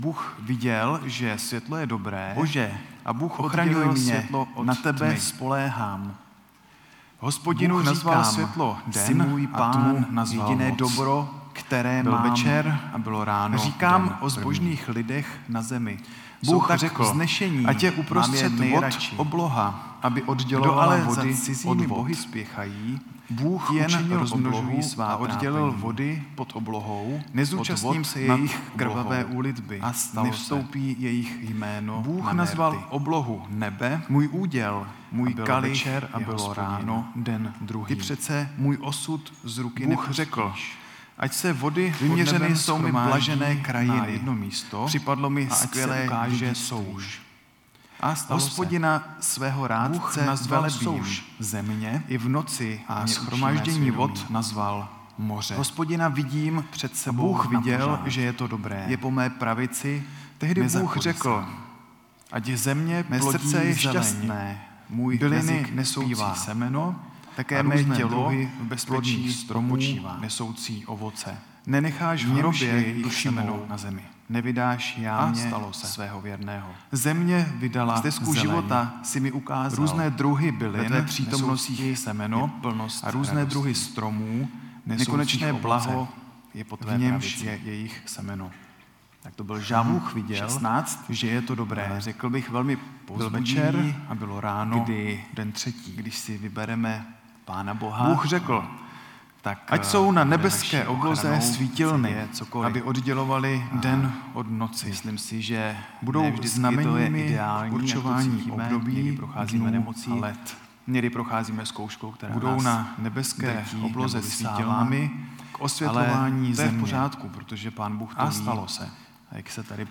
Nedělní bohoslužby